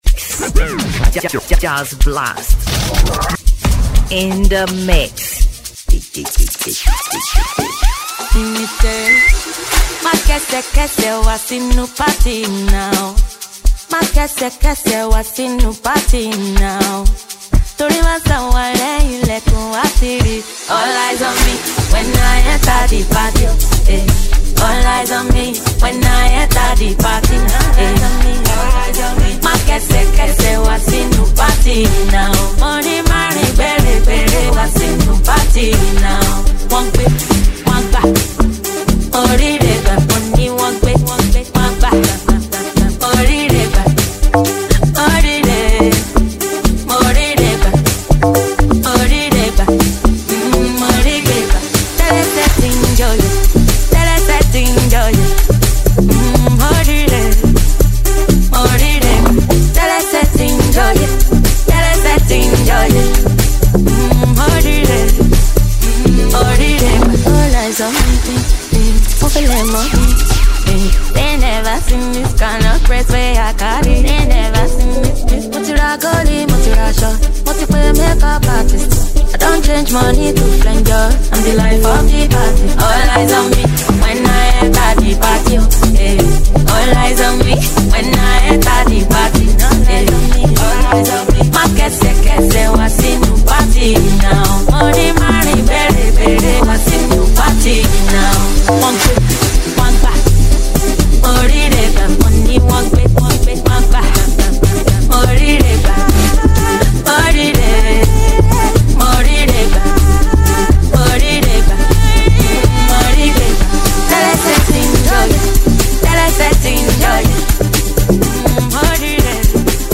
Nigerian Yoruba Fuji track
Yoruba Fuji Sounds